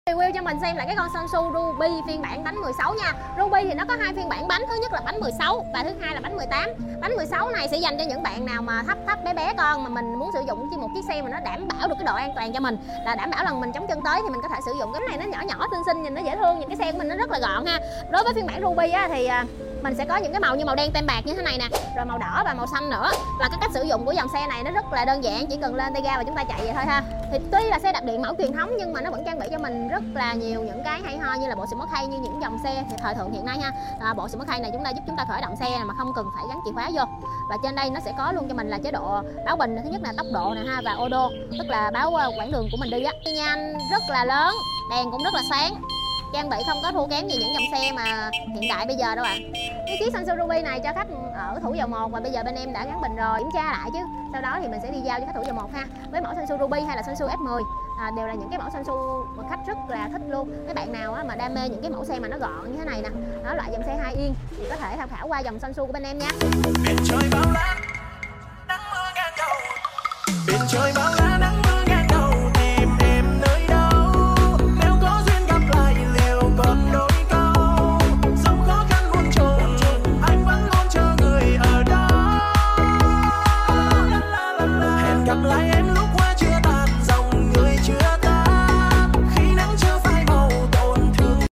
Review xe đạp điện Sonsu sound effects free download